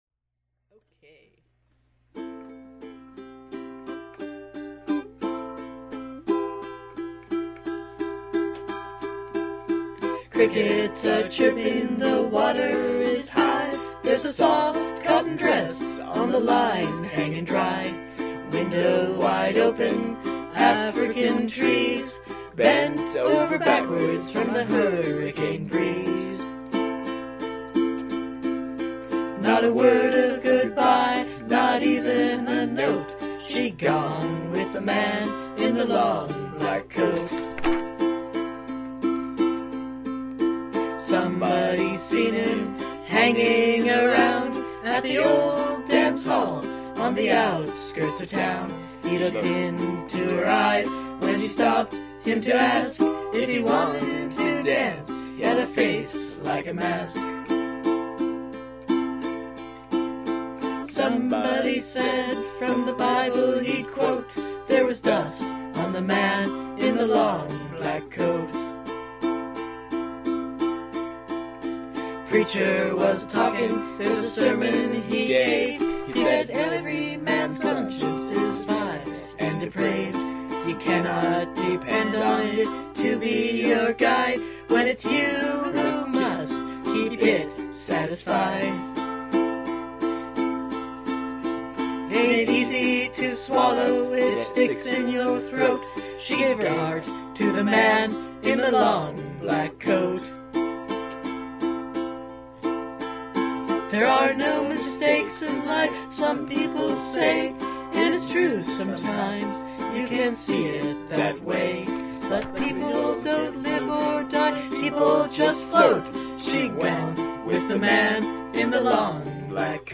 LOVELY SINGING VOICE.
ukulele